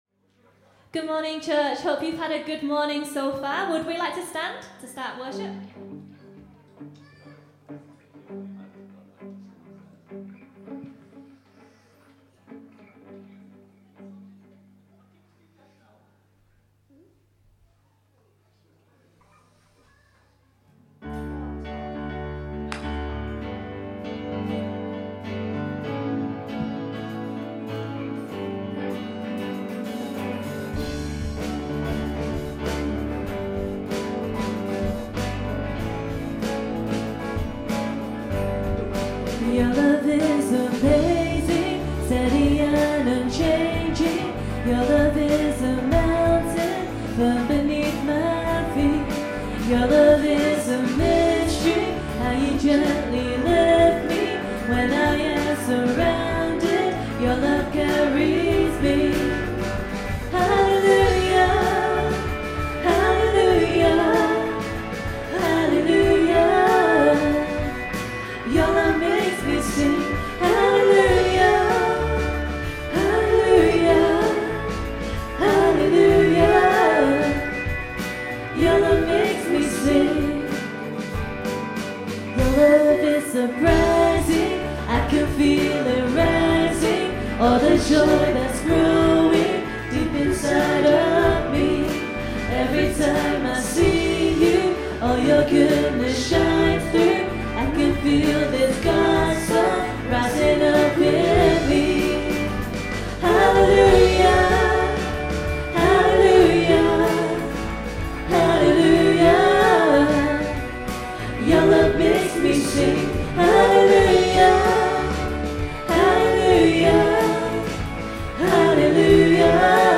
Worship March 29, 2015 – Birmingham Chinese Evangelical Church
Lead/Keys
Drums
Bass